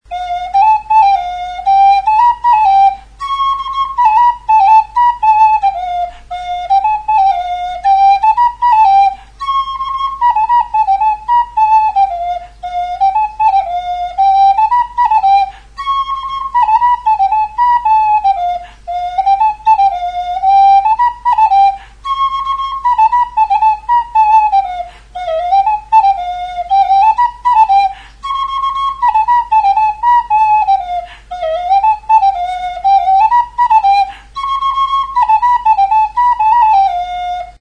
Aerophones -> Flutes -> Fipple flutes (two-handed) + kena
FLAUTA; PITO
Dena itxita Re ematen du.